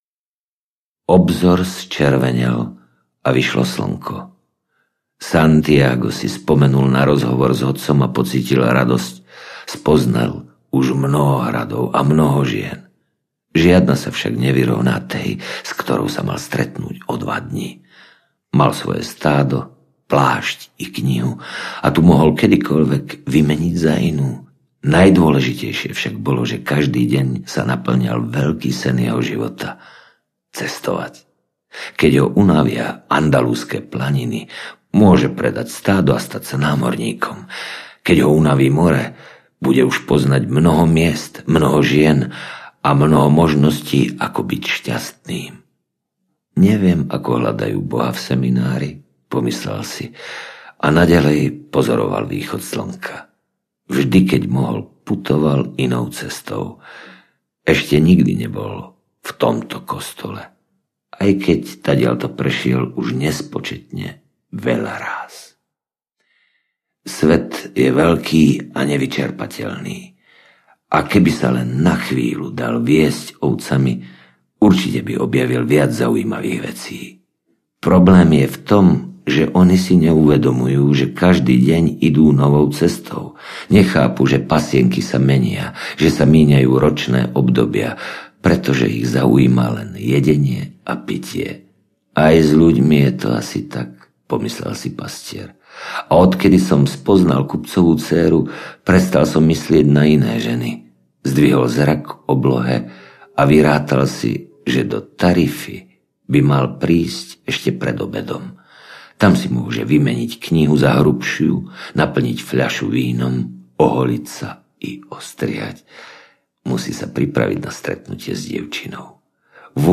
Alchymista audiokniha
Ukázka z knihy
• InterpretMarián Geišberg